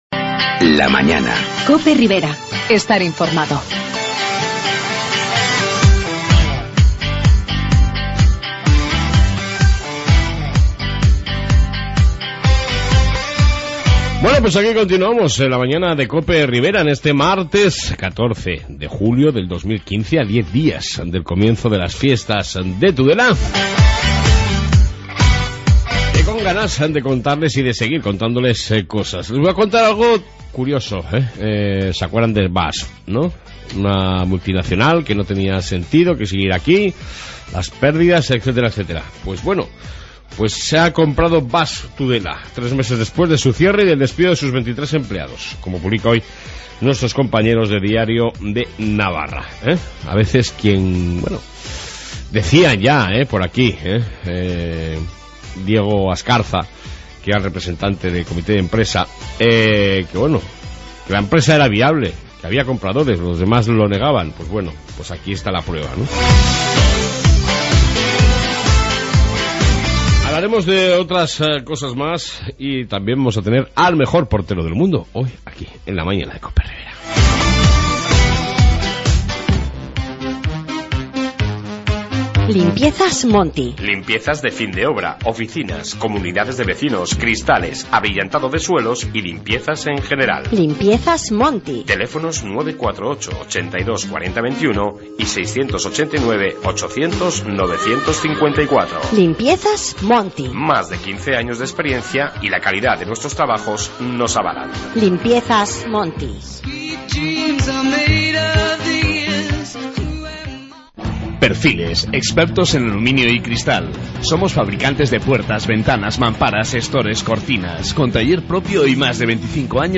AUDIO: Noticias Riberas + Entrevista